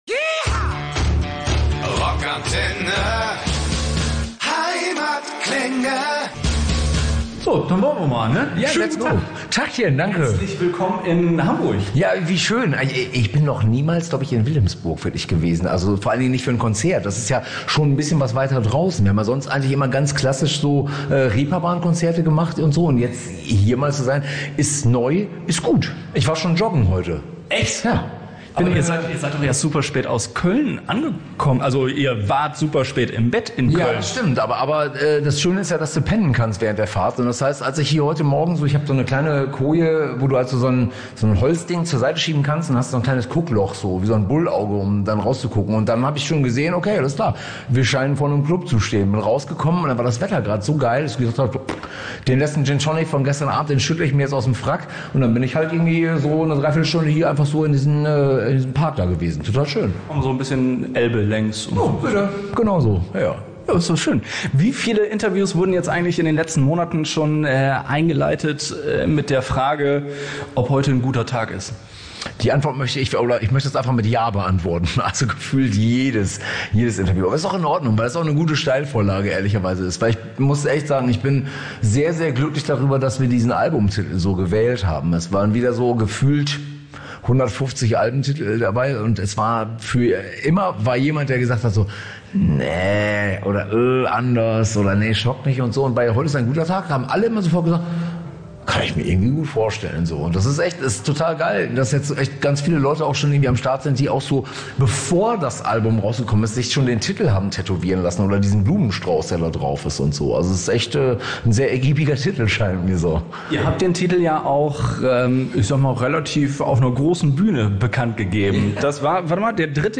Er schreibt einen Song nach dem anderen: Wir haben Herman Rarebell im exklusiven ROCK ANTENNE Interview gefragt, wo er seine Inspiration für das Songwriting hernimmt, welche Songs ihn in seiner ...